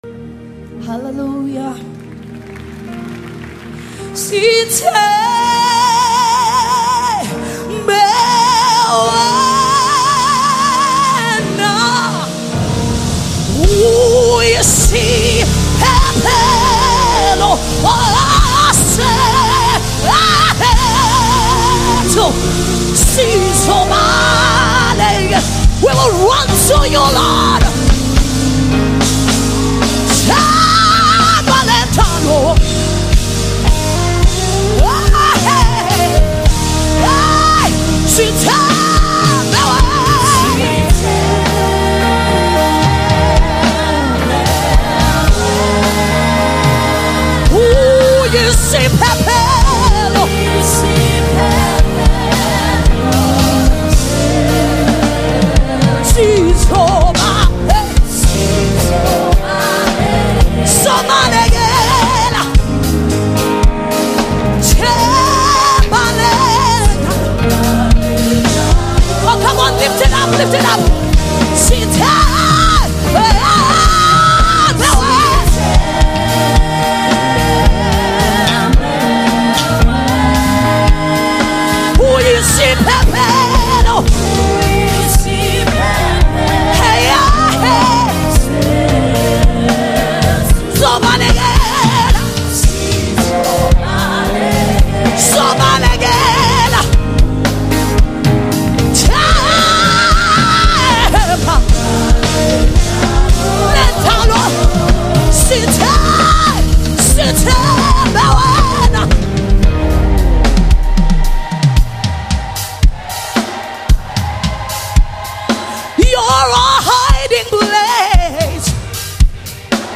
Renowned South African gospel group
A deeply moving and anointed worship song
Recorded live for an immersive worship experience
📅 Category: South African Worship Song